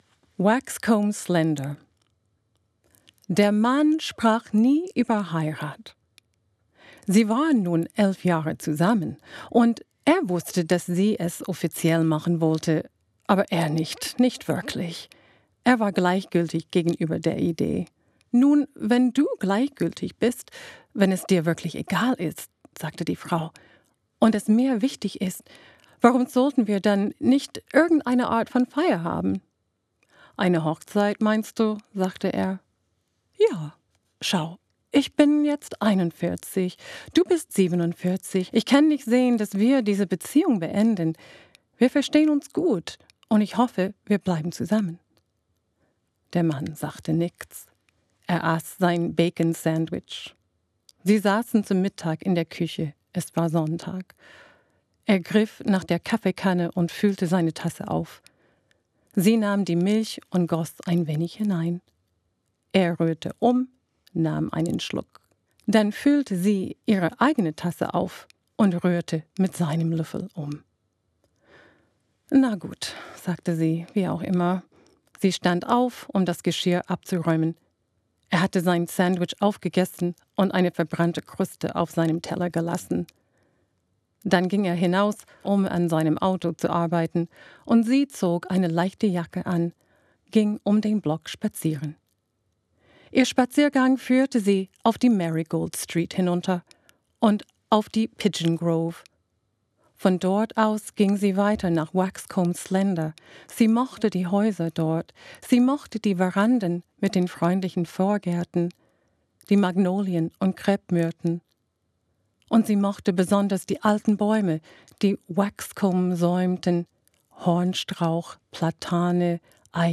Hörprobe aus „Monkey Bones – Erzählungen“: Waxcomb Slender (Deutsch):